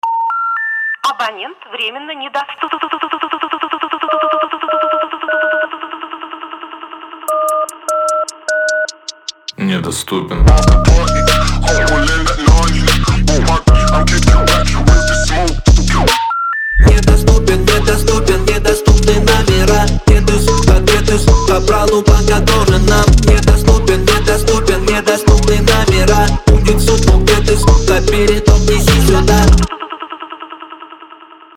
мощные басы
качающие
грубые
фонк